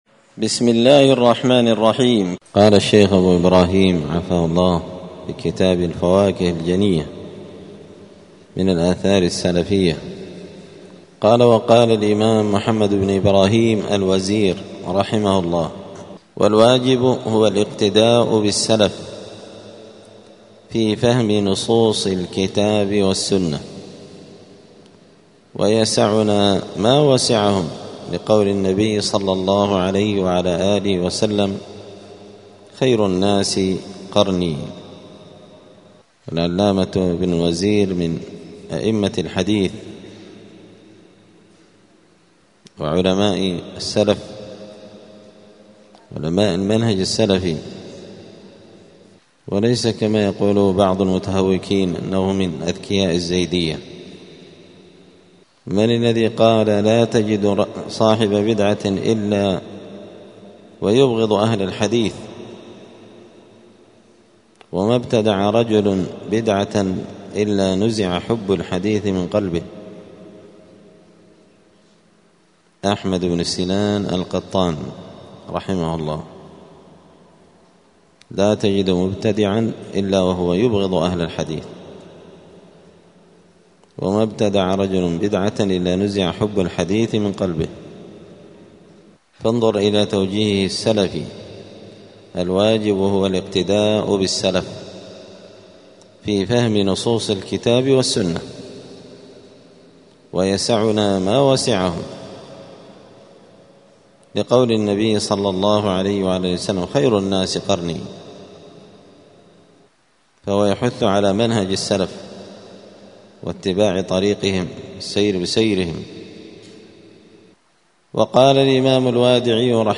دار الحديث السلفية بمسجد الفرقان بقشن المهرة اليمن
*الدرس الخامس والستون (65) {باب عناية السلف بتطبيق السنة}*